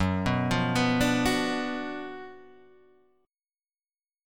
GbM7sus4#5 chord